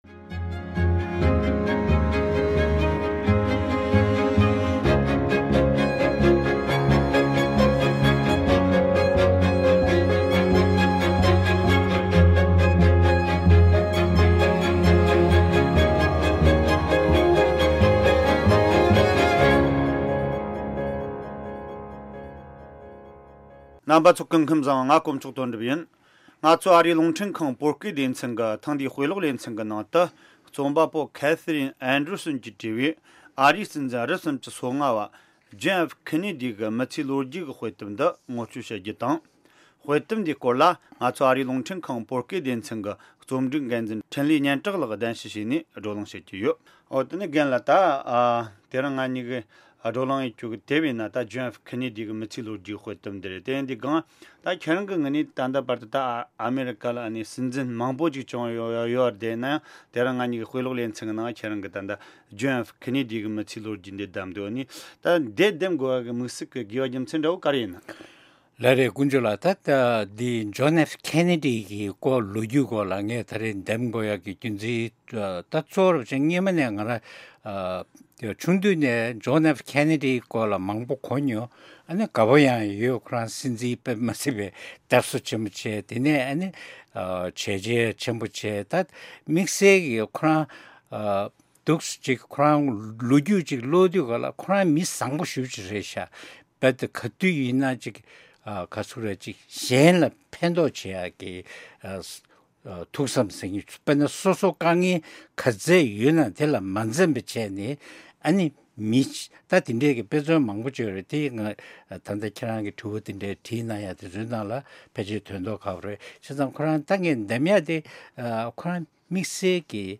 བགྲོ་གླེང་བྱས་ཡོད།